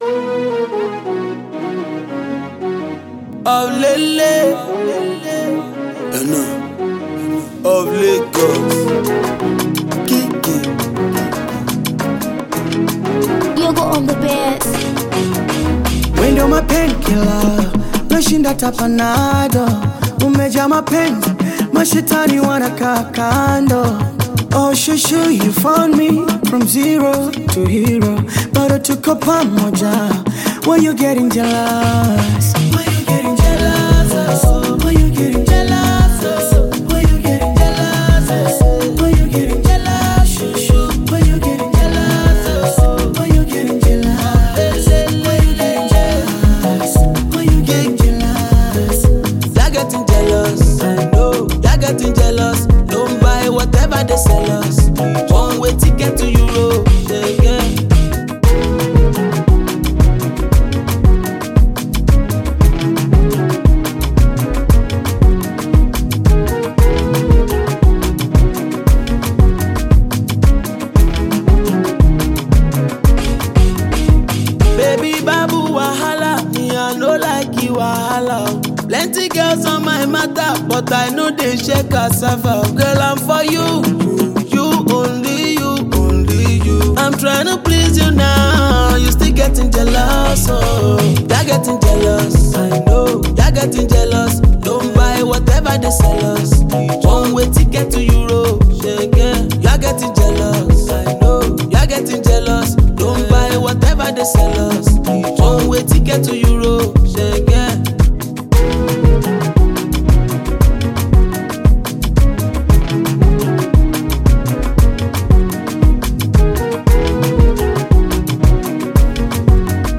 Afro-Pop
with a unique rhythm